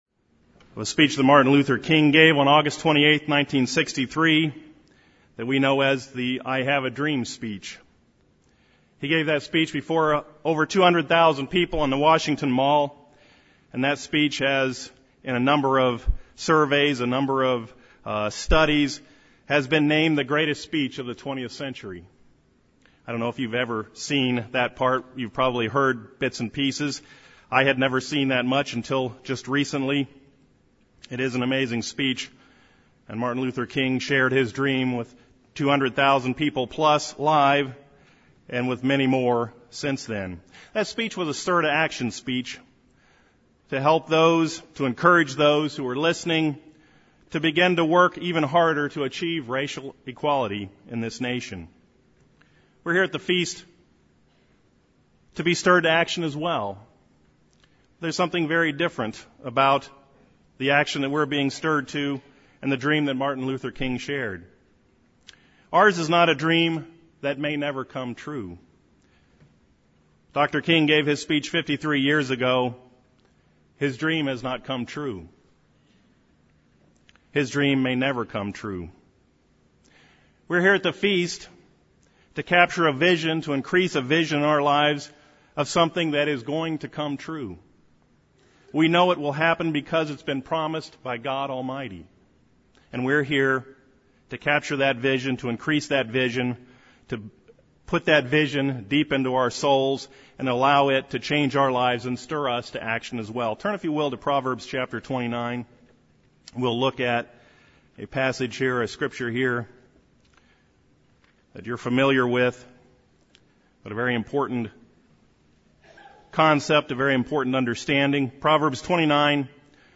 This sermon was given at the Gatlinburg, Tennessee 2016 Feast site.